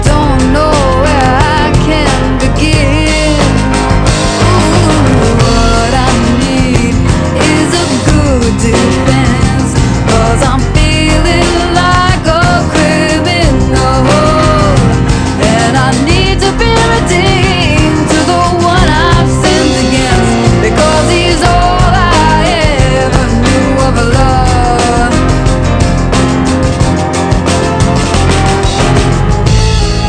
Above all, this is soul music: moving and candid.
The sinner and swagger